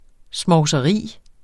Udtale [ smɒwsʌˈʁiˀ ]